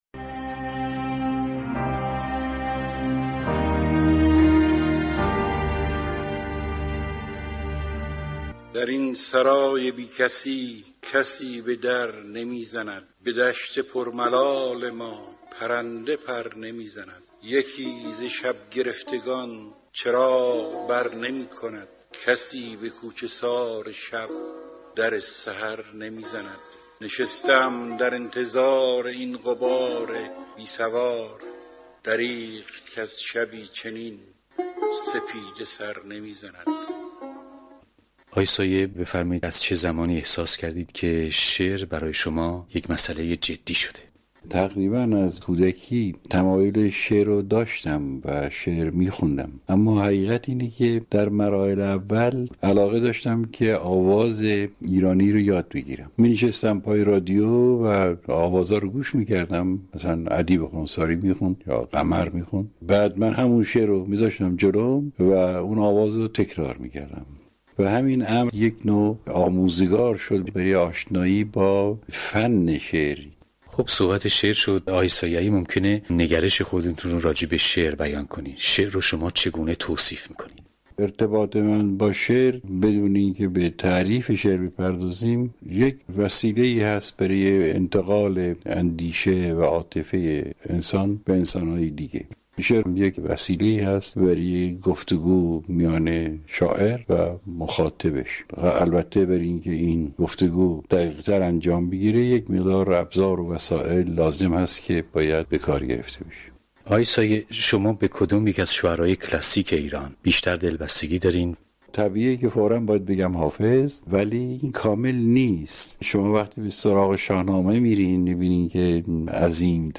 گفت‌وگوی چندی پیش